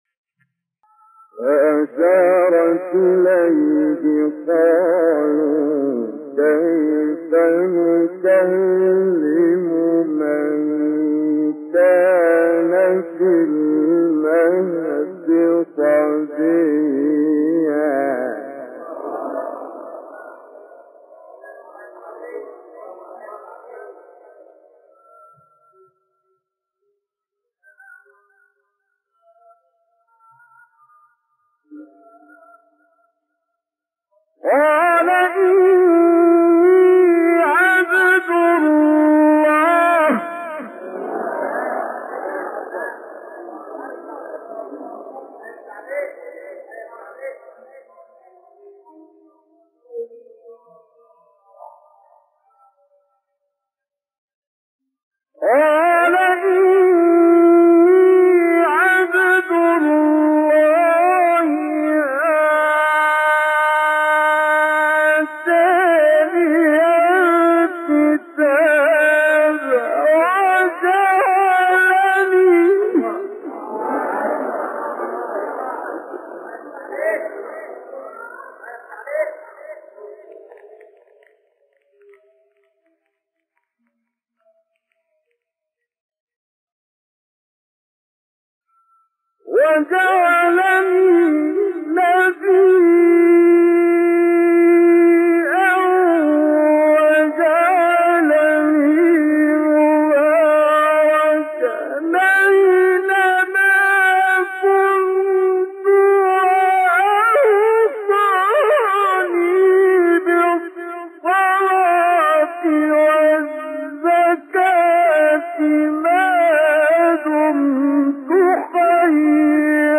سوره : مریم آیه: 29-34 استاد : کامل یوسف مقام : مرکب خوانی (چهارگاه * رست) قبلی بعدی